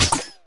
minig_dryfire_01.ogg